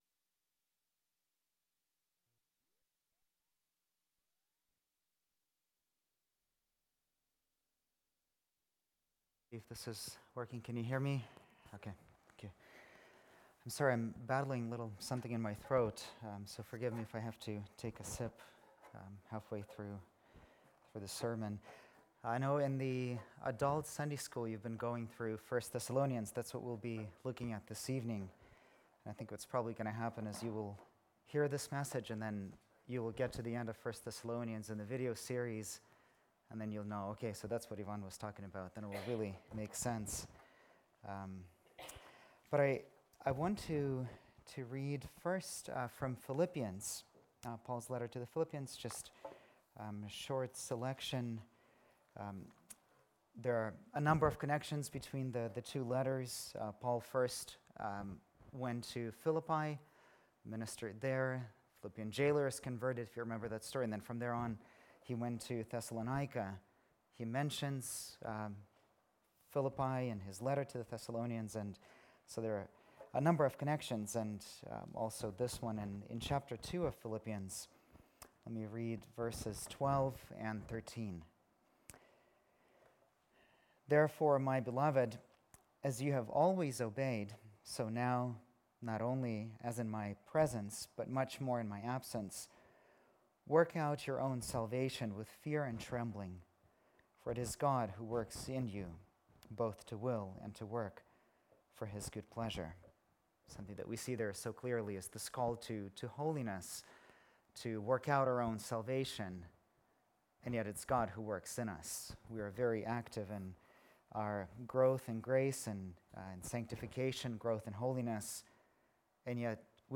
February 12, 2017 (Sunday Evening)
Series: Evening service sermons 2017